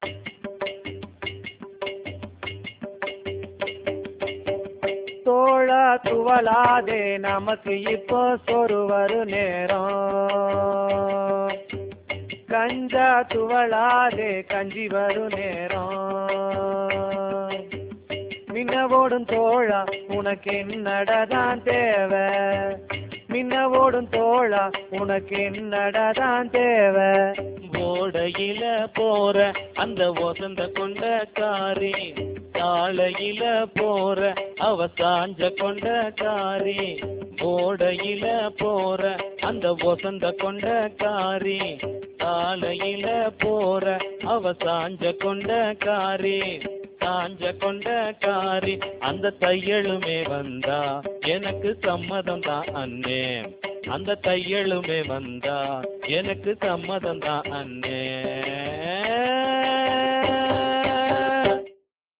3.4.1 வேளாண்மைத் தொழிற்பாடல்கள்
ஏற்றப் பாடலின் மற்றொரு துணுக்கு வருமாறு.